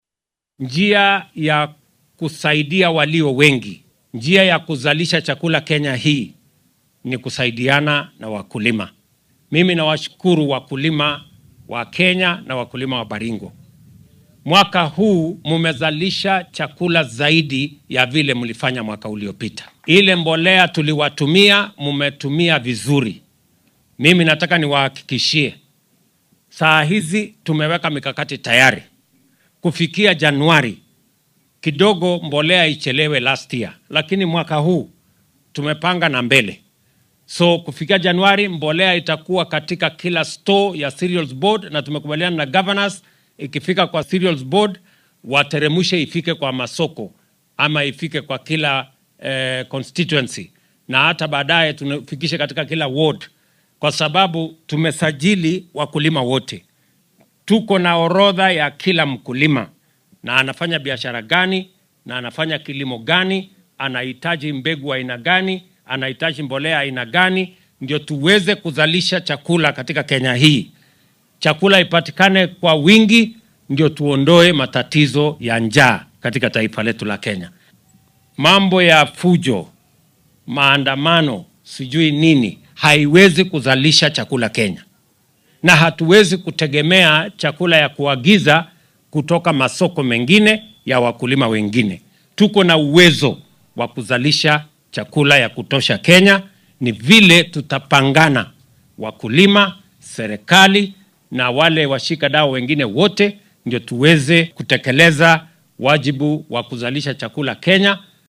Hoggaamiyaha dalka ayaa soo hadal qaaday taageeridda beeralayda si sare loogu qaado soo saaridda cunnada.